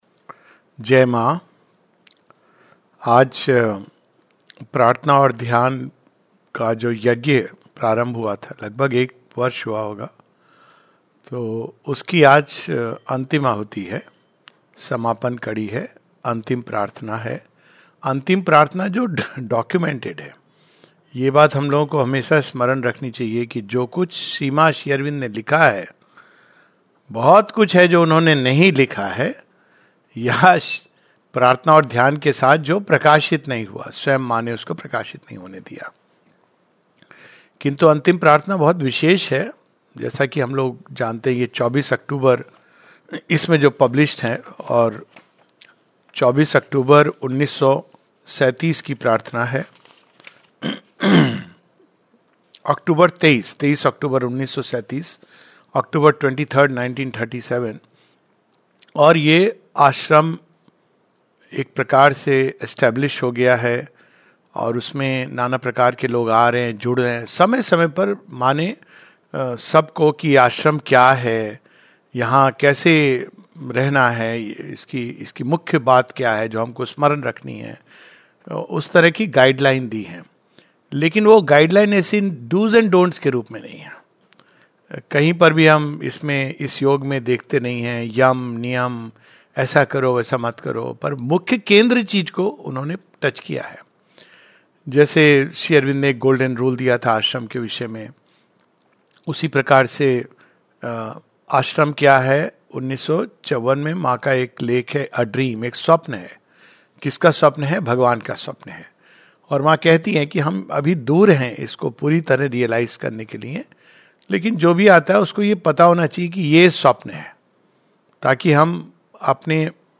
[Serving the Divine] In this last webinar talk on the Prayers and Meditations series in Hindi we take up the last prayer as recorded in the book.